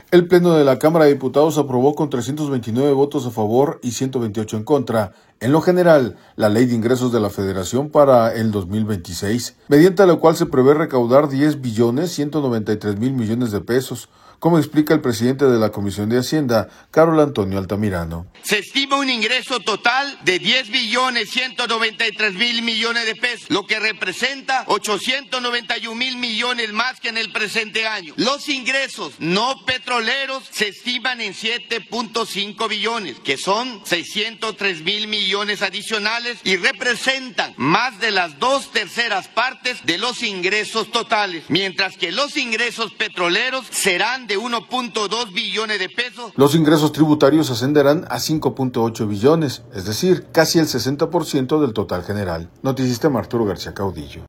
El Pleno de la Cámara de Diputados, aprobó con 329 votos a favor y 128 en contra, en lo general, la Ley de Ingresos de la Federación para el 2026, mediante la cual se prevé recaudar diez billones 193 mil millones de pesos, como explica el presidente de la Comisión de Hacienda, Carol Antonio Altamirano.